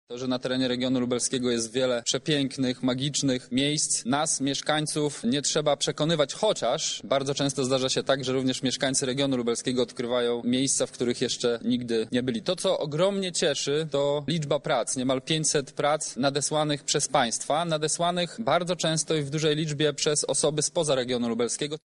Miejsca w regionie lubelskim stają się coraz częstszą destynacją dla turystów – mówi wicemarszałek województwa lubelskiego Michał Mulawa: